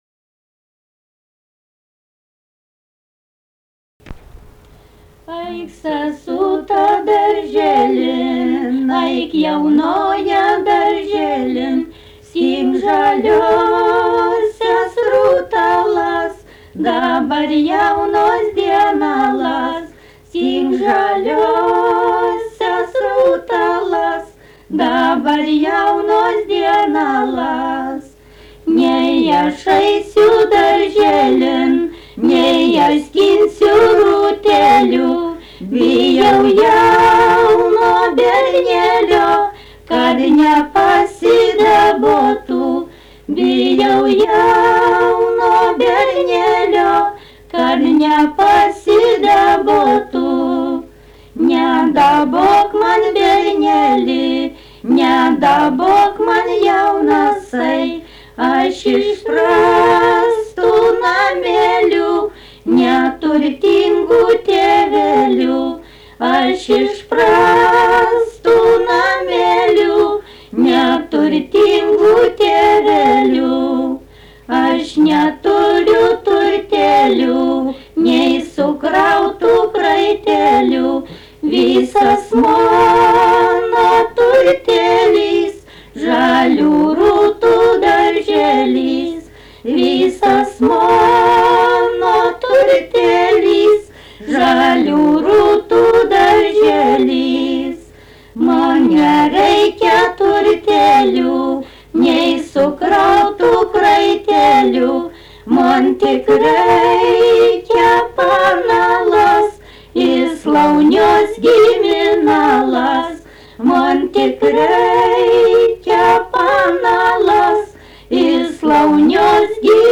smulkieji žanrai
Erdvinė aprėptis Kietaviškės
Atlikimo pubūdis vokalinis